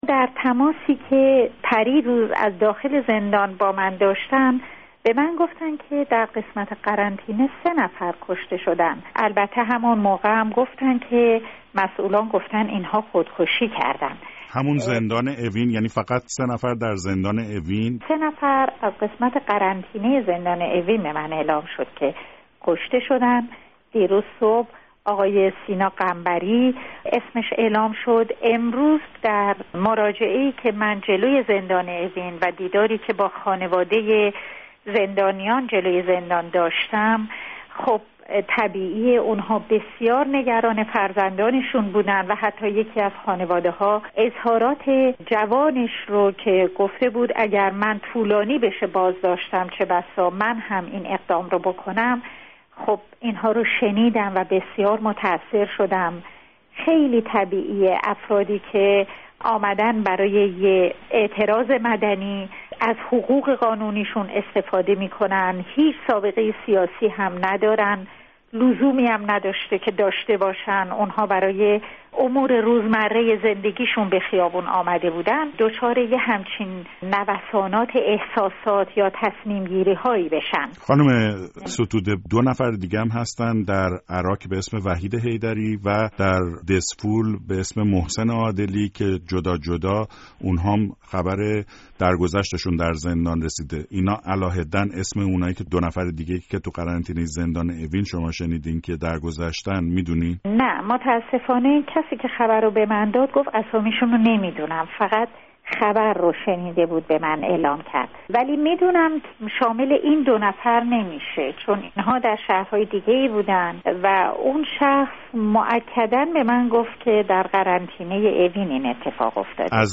گفت‌وگو با نسرین ستوده، درباره گزارش‌هایی از چند «خودکشی» در زندان در پی اعتراضات